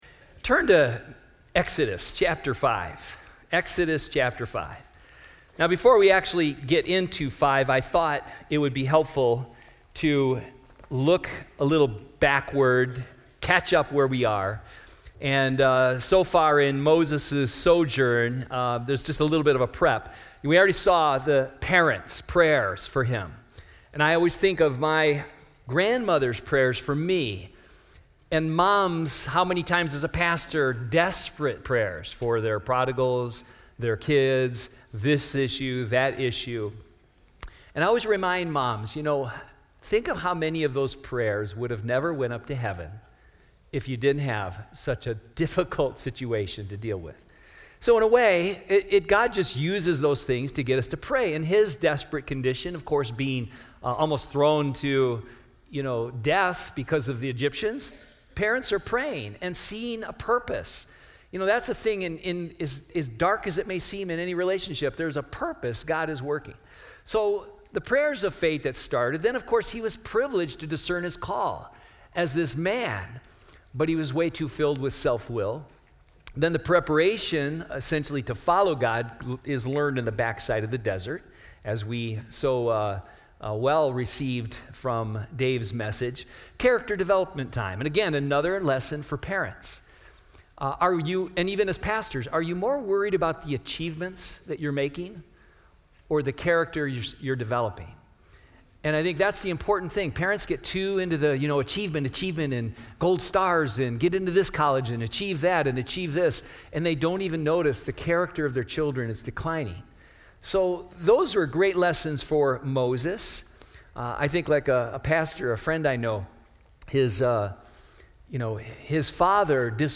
2020 Southwest Pastors and Leaders Conference